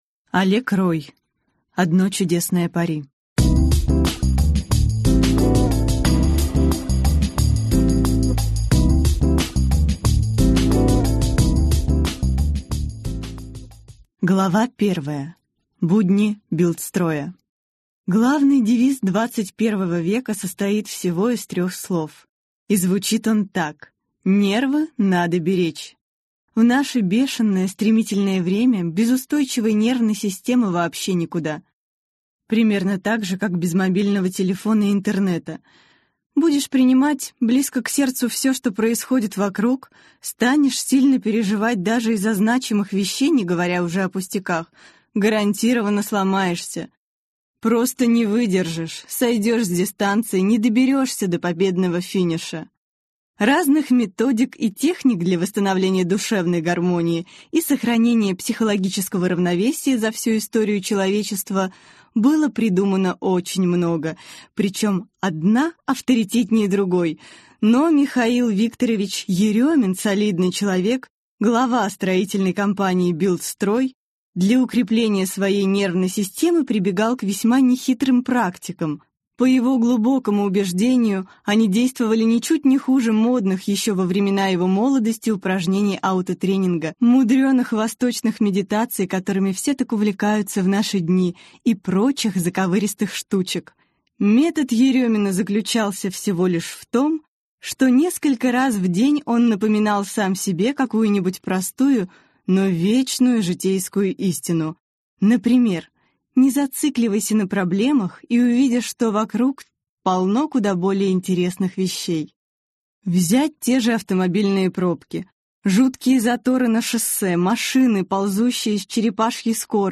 Аудиокнига Одно чудесное пари | Библиотека аудиокниг